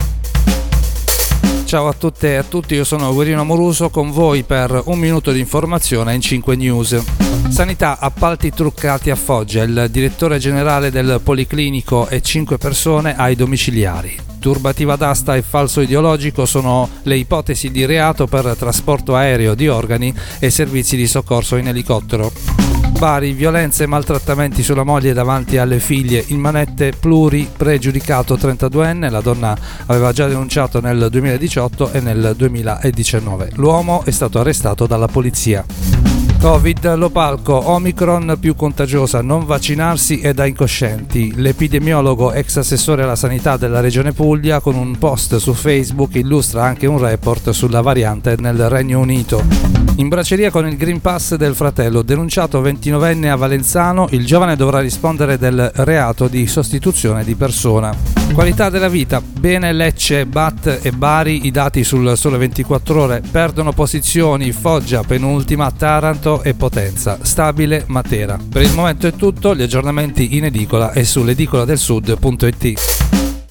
Giornale radio alle ore 13.